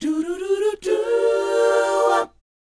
DOWOP C 4A.wav